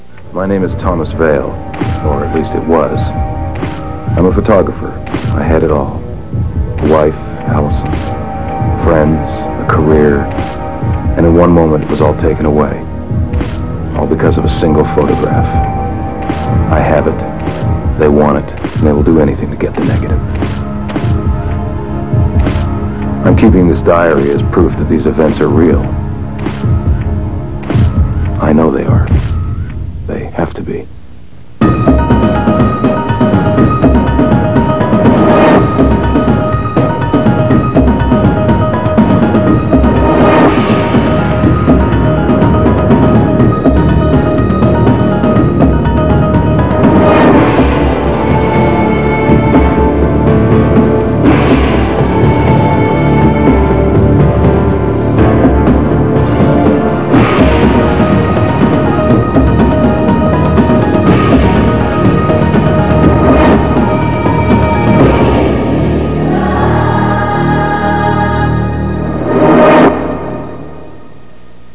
Speech & music